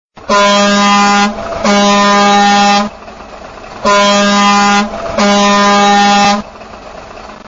truck-horn_24961.mp3